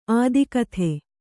♪ ādikathe